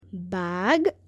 tiger.mp3